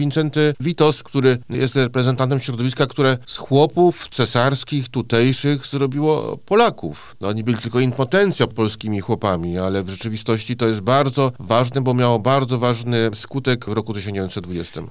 O tym, z czego szczególnie zasłynął nasz bohater, mówi zastępca prezesa IPN dr Mateusz Szpytma: